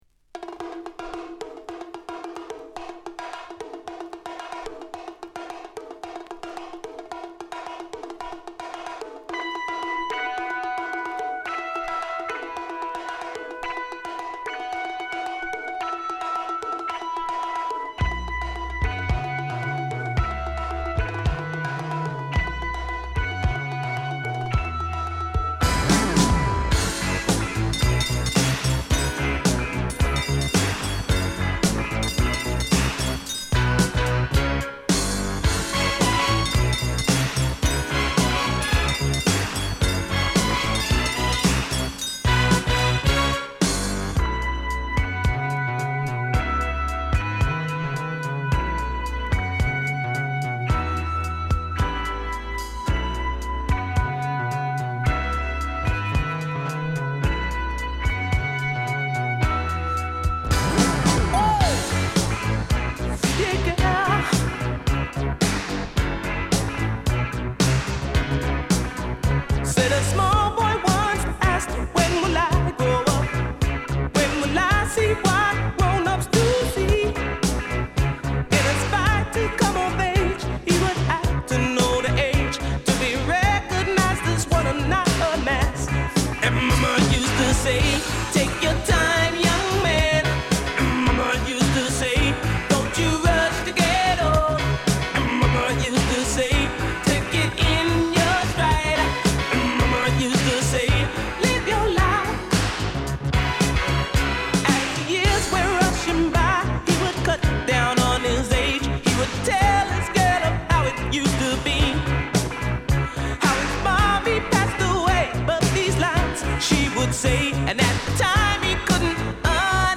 パーカッションのイントロから、お馴染みのシンセメロ＆ベースメロに、パワフルなヴォーカルが乗る名曲！！